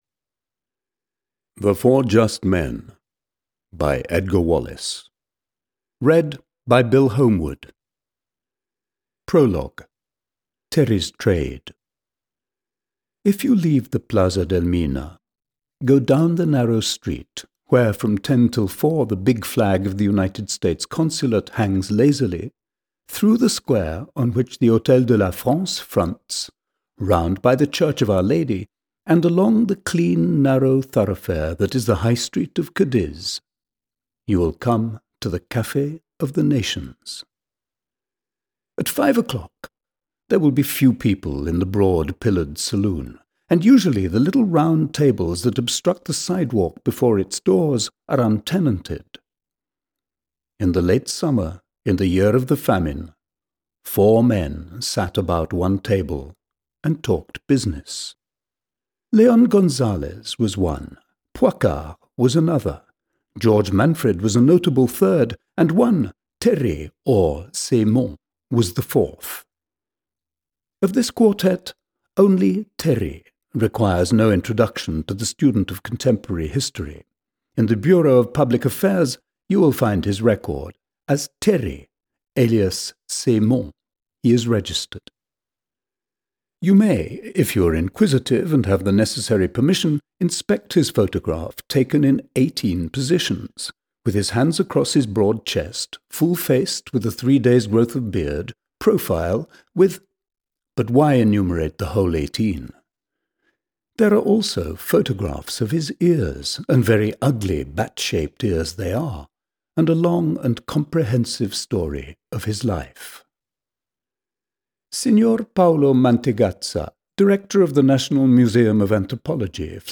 The Four Just Men (EN) audiokniha
Ukázka z knihy